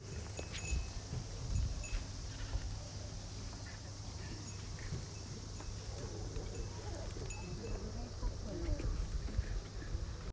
氷屋さんで順番待ち 風鈴、蝉、時々お客さんの声